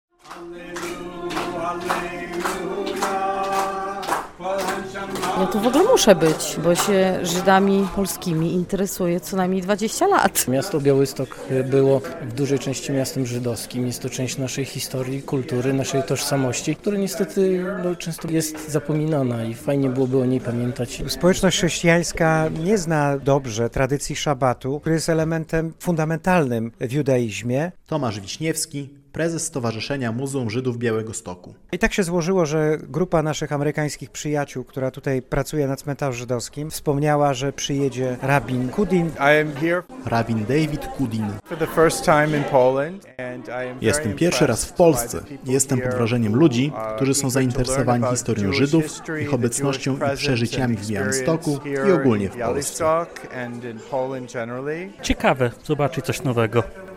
Wieczór szabatowy w Białymstoku - relacja